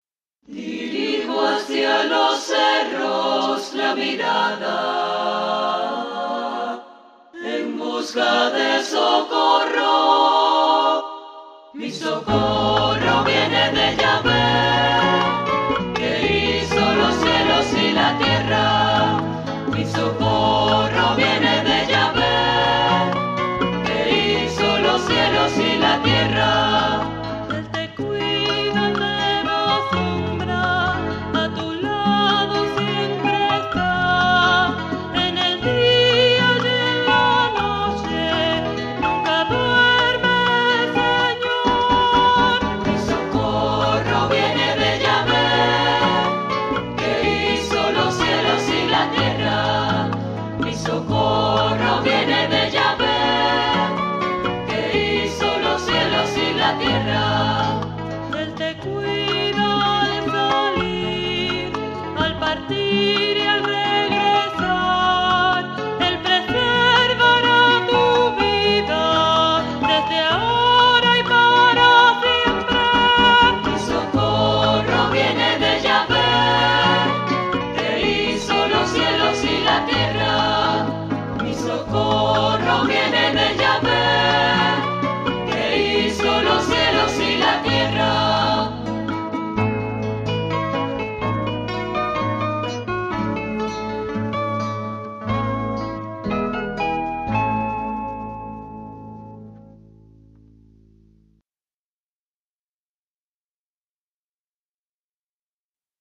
Mi Socorro (bolero) Letra basada en Salmo 121 Música: Pedro Jiménez (Cuba) Dirijo hacia los cerros la | Red de Liturgia | CLAI